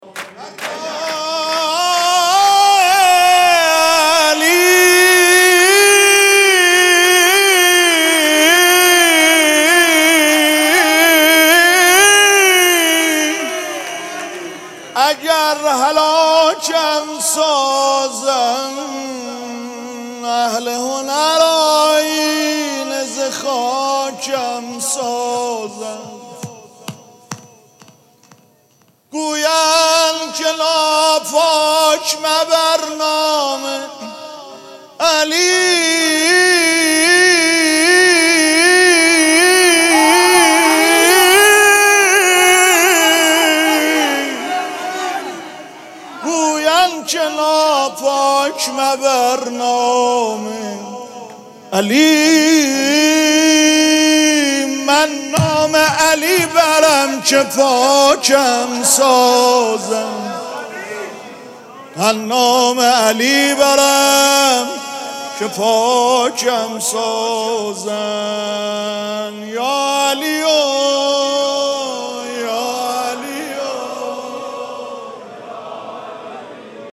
مراسم جشن ولادت حضرت زینب (سلام الله علیها)
‌‌‌‌‌‌‌‌‌‌‌‌‌حسینیه ریحانه الحسین سلام الله علیها
مدح